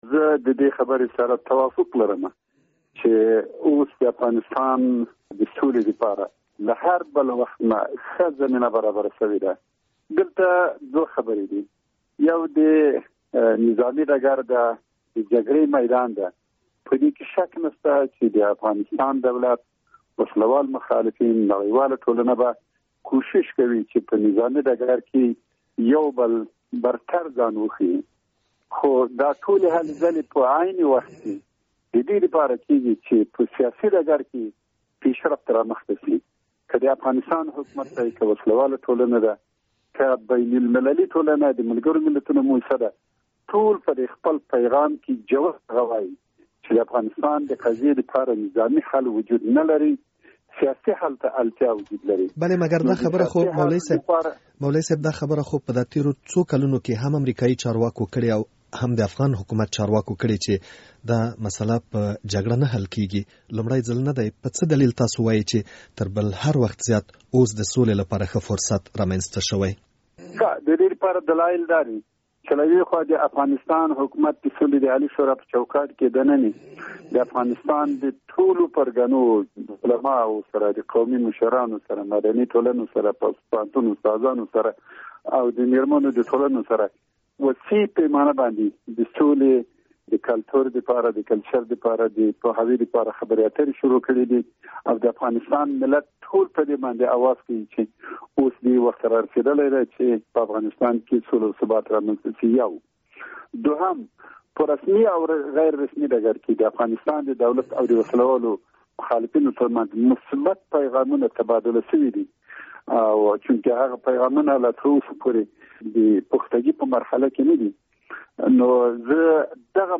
له ښاغلي عبدالحکیم مجاهد سره مرکه.